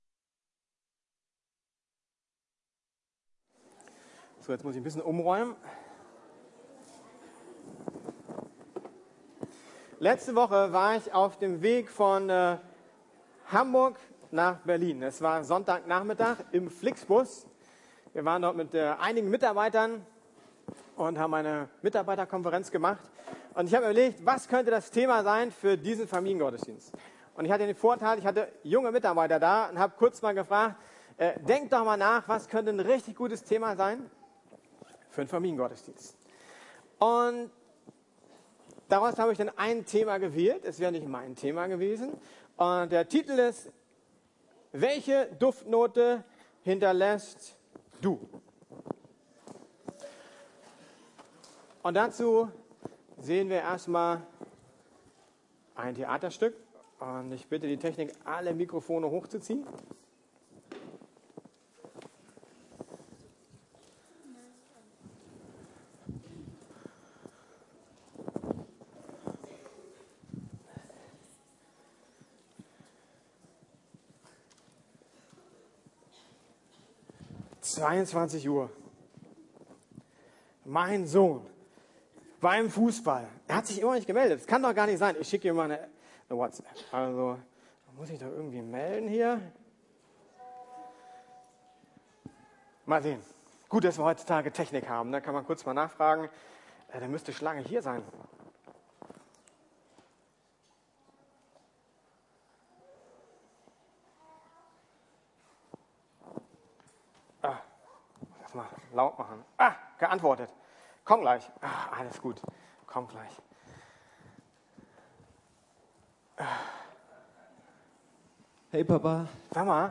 Welche Duftwolke hinterlässt Du? ~ Predigten der LUKAS GEMEINDE Podcast